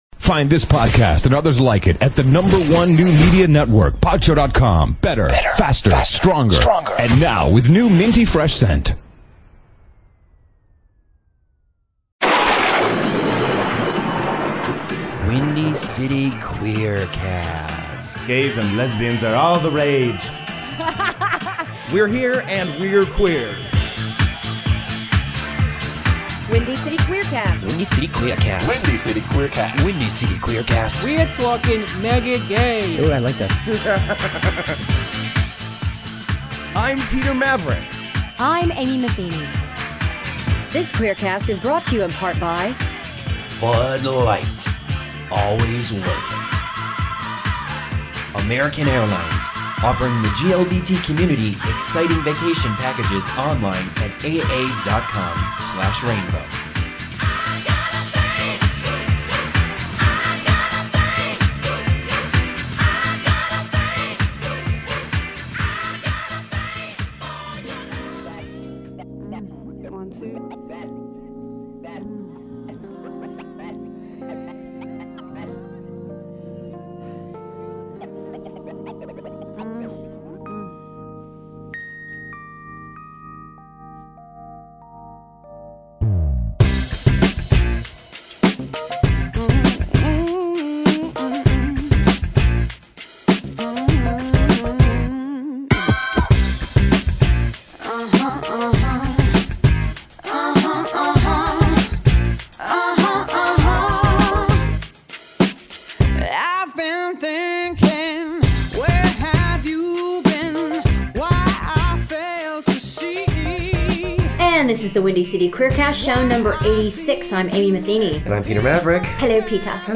Guest: Village Voice columnist Michael Musto drops in to talk with us about his new...